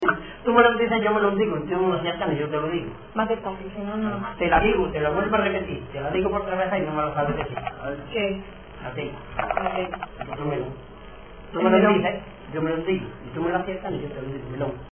Materia / geográfico / evento: Adivinanzas Icono con lupa
Santa Cruz del Comercio (Granada) Icono con lupa
Secciones - Biblioteca de Voces - Cultura oral